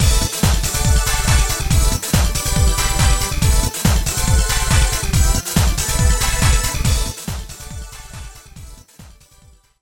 ◆ステレオ音源をDPCM化すると定位がおかしくなるんじゃと思ったが以外と維持できているので結構使えるかも。
music_4bitdpcm.mp3